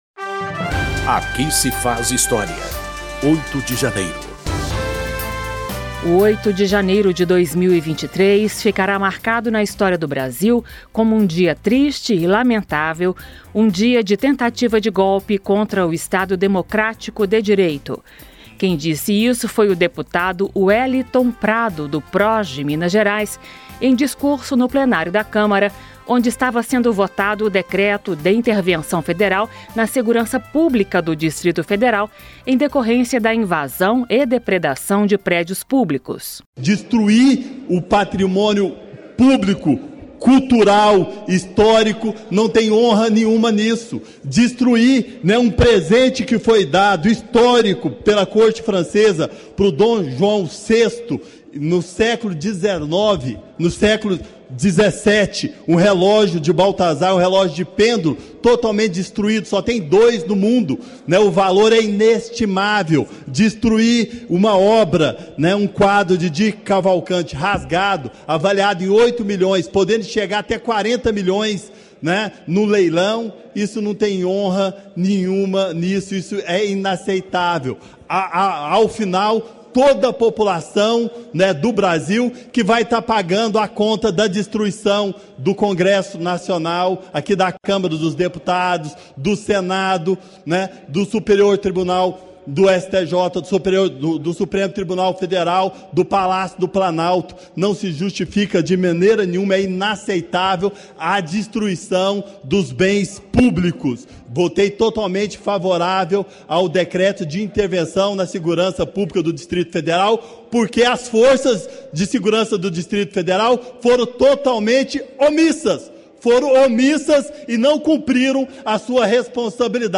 Em 09/01/23, a Câmara dos Deputados autorizou a intervenção federal na segurança pública do DF. O programa da Rádio Câmara Aqui se faz História resgata 30 discursos de deputados na sessão.
Dep. Weliton Prado (Solidariedade-MG), falou como líder do PROS:
Um programa da Rádio Câmara que recupera pronunciamentos históricos feitos no Parlamento por deputados ou agentes públicos, contextualizando o momento político que motivou o discurso.